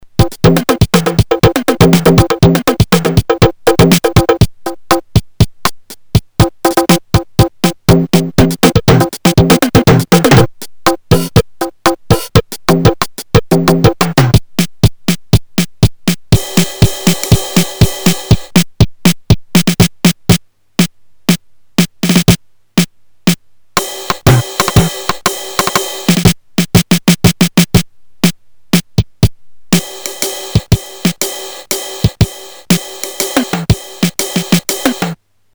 Budget rompler drum pad with basic functions, rhythm patterns and a Midi output feature.
edit VOICE a 4 voices polyphony system based on low-fidelity PCM samples (companded 8 bits?).
Just 12 PCM sounds onboard: kick, snare, tom (x3), ride, handclap, cowbell, rimshot, hihat, conga* (*only accessible only preset patterns)
pattern rock, salsa, march, country, dixie, pop, waltz, shuffle, afro, rhumba, disco, pola, chacha, ballads and ondo